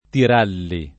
tir#lli] top. — forma ant. del nome di Castel Tirolo (A. A.): A piè de l’Alpe che serra Lamagna Sovra Tiralli [